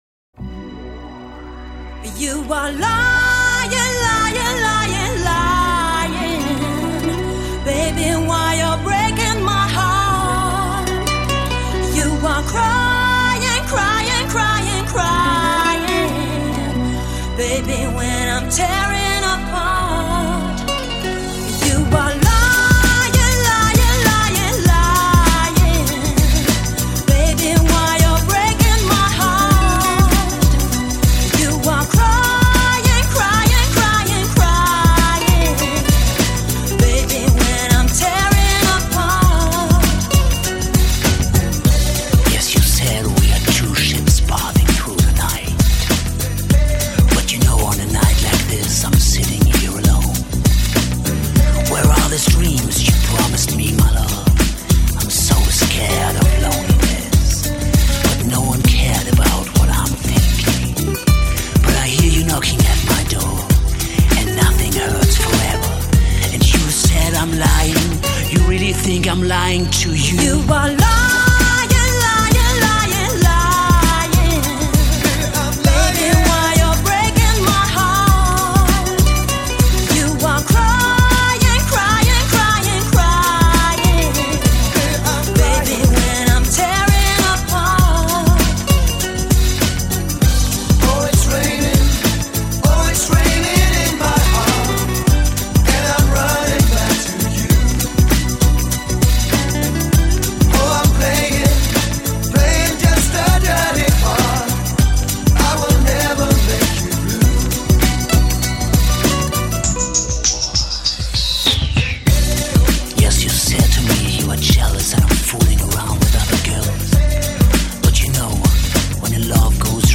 Жанр: EuroDisco